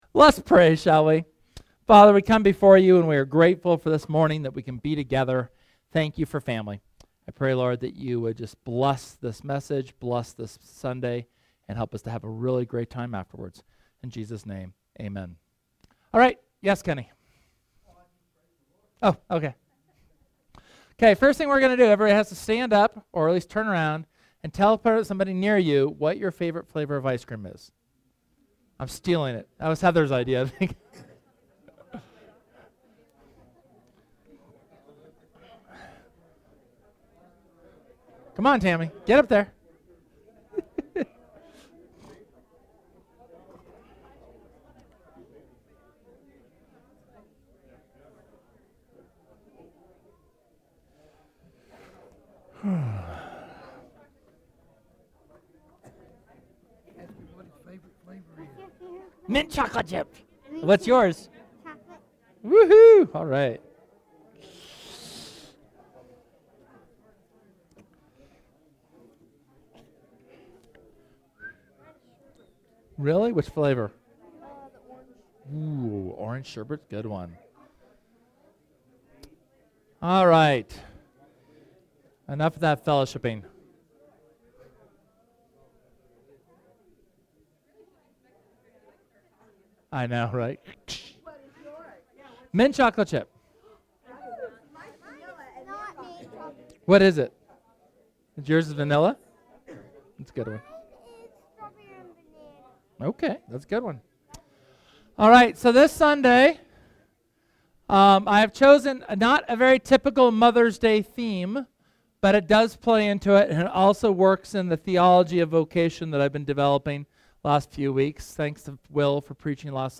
In this sermon, we take time to look at our culture's changing perspective on heroes and how we can be heroes where we've been planted.